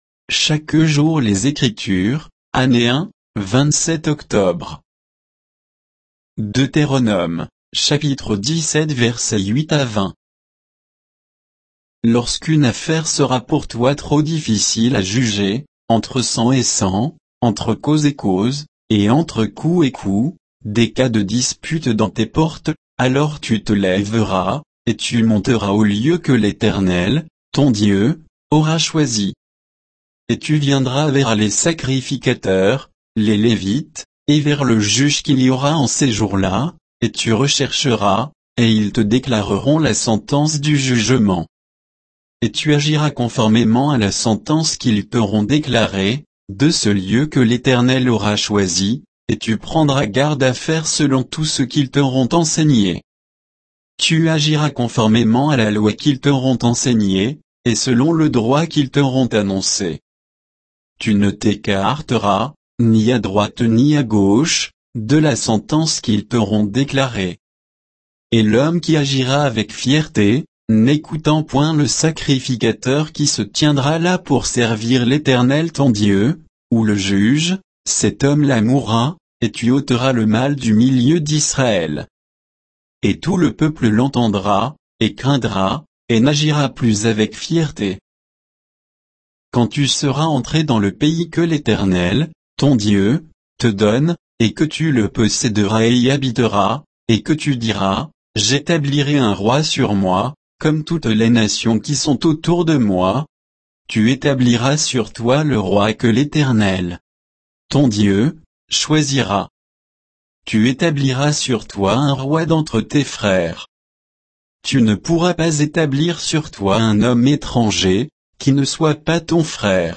Méditation quoditienne de Chaque jour les Écritures sur Deutéronome 17, 8 à 20